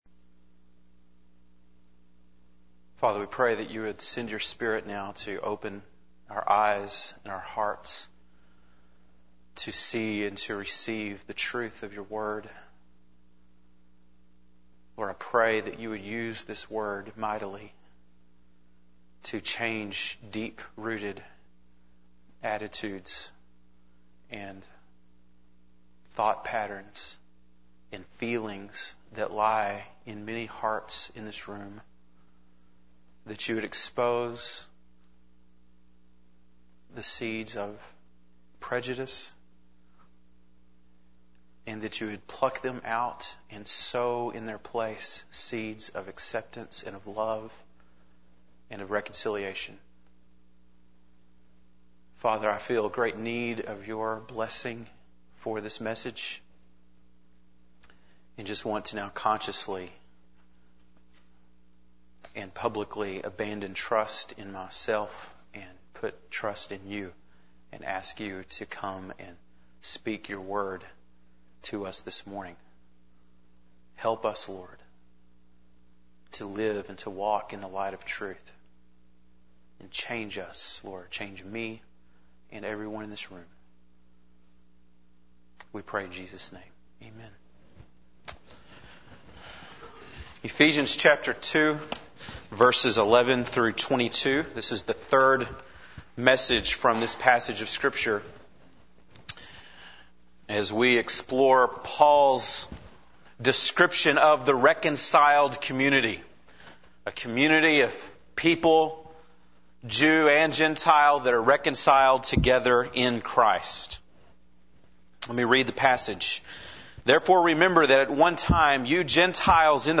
February 20, 2005 (Sunday Morning)